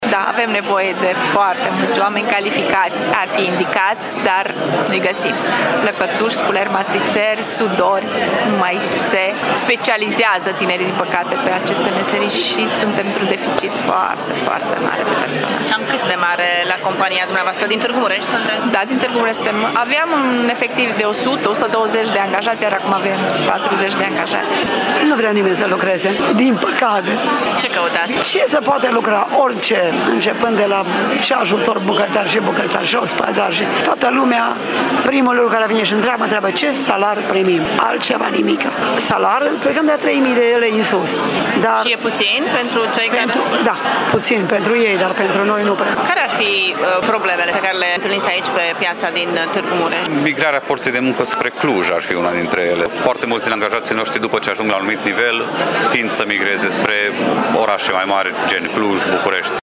Angajatorii se plâng că nu găsesc oameni calificați, nici tineri care să se specializeze în diferite meserii: